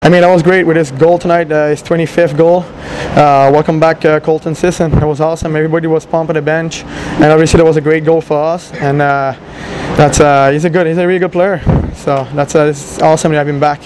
Let’s dive into last night’s post-game interviews!
laughing in background